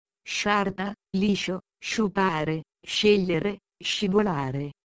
letters pronunciation examples English translation /∫/